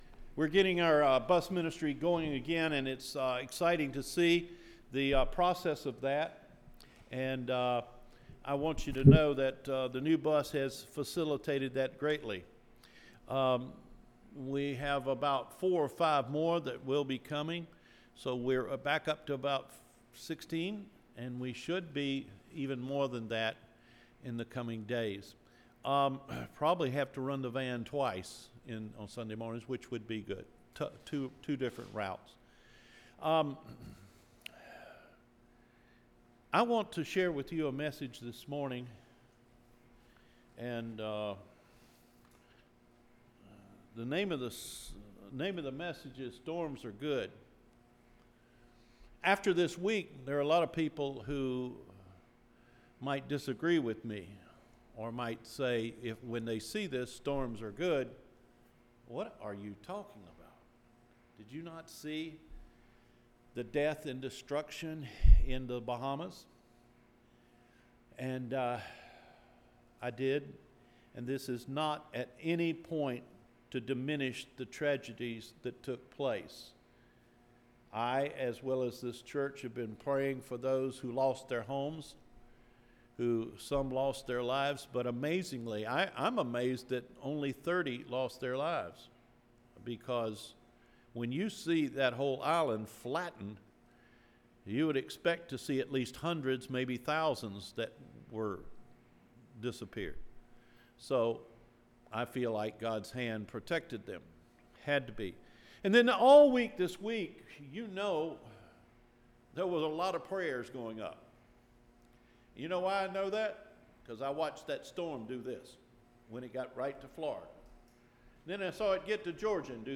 STORMS ARE GOOD? – SEPTEMBER 8 SERMON – Cedar Fork Baptist Church
STORMS ARE GOOD? – SEPTEMBER 8 SERMON